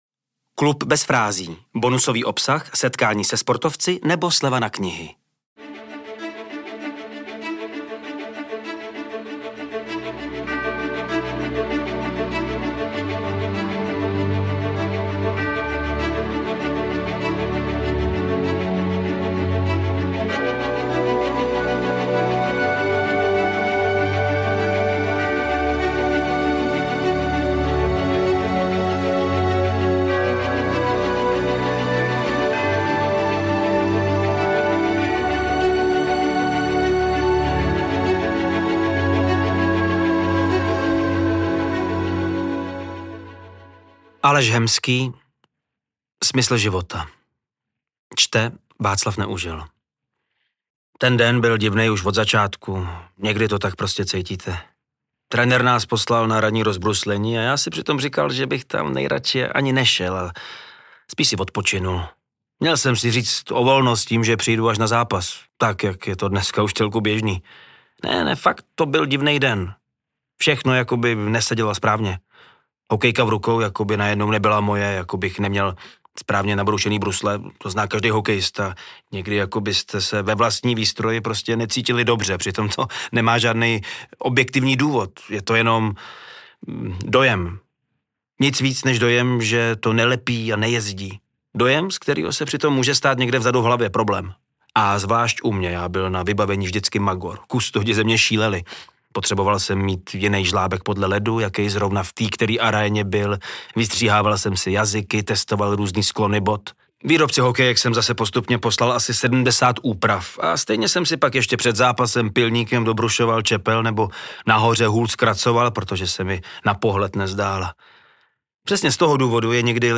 Mluví o tom ve svém příběhu, který namluvil jedinečný herec Václav Neužil .